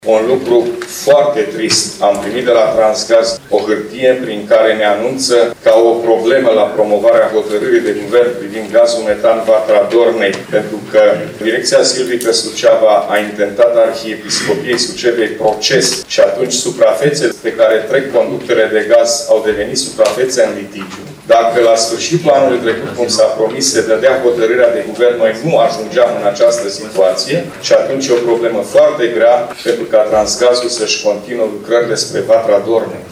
Președintele Consiliului Județean, GHEORGHE FLUTUR, a declarat că, deocamdată, nu se știe când va fi reluat proiectul.